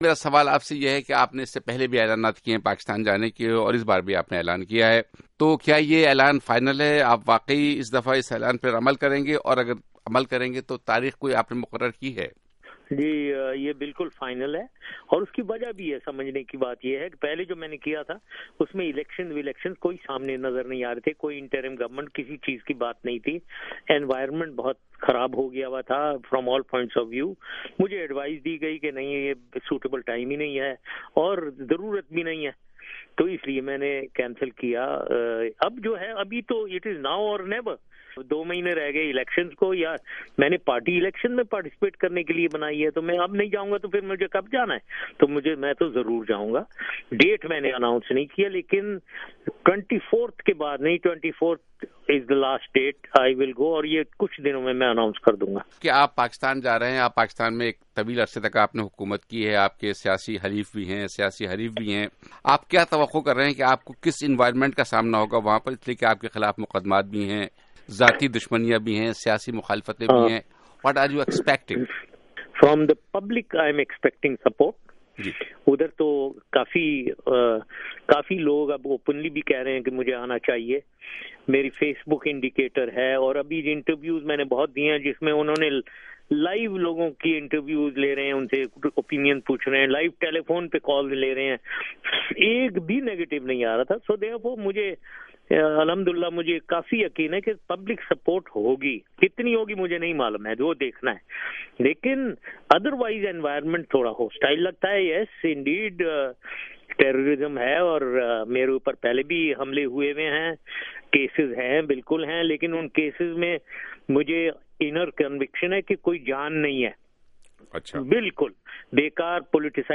سابق صدر پرویز مشرف کا انٹرویو